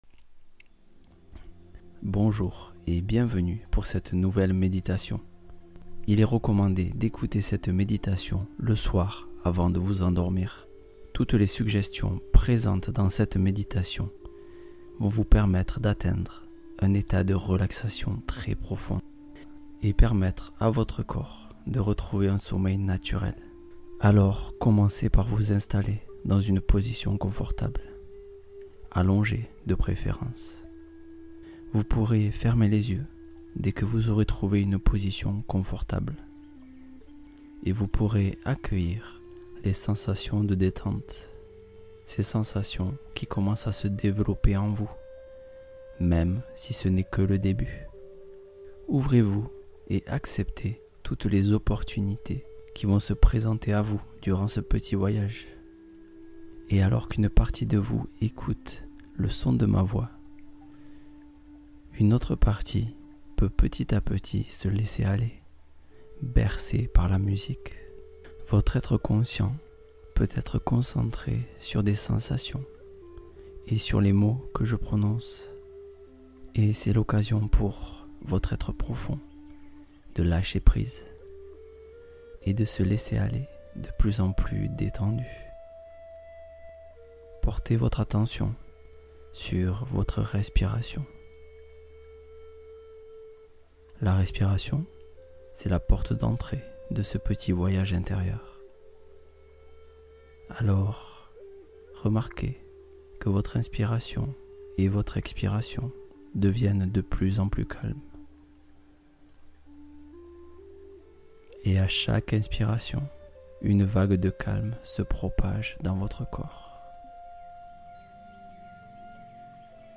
Confiance absolue activée — Méditation guidée à fort impact